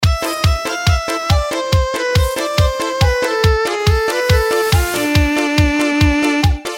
• Качество: 128, Stereo
dance
без слов
ретро